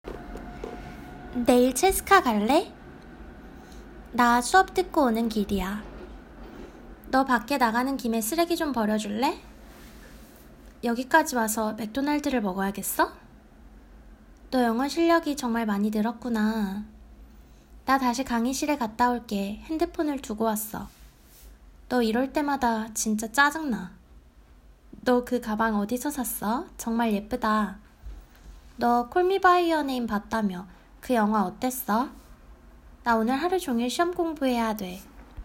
6_sentence_drill.m4a